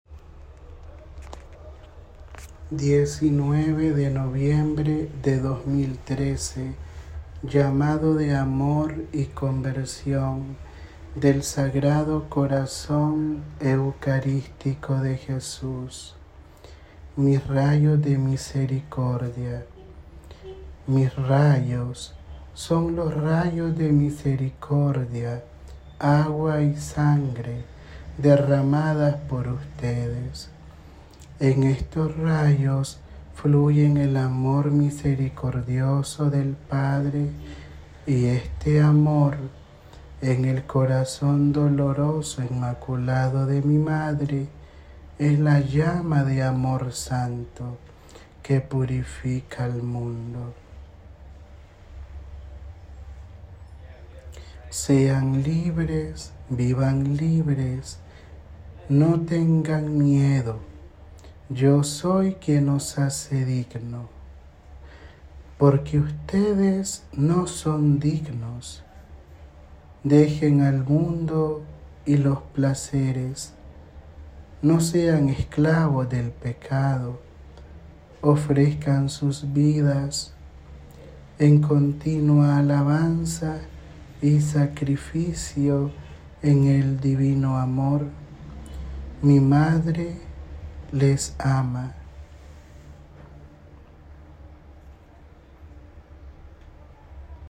Audio da Mensagem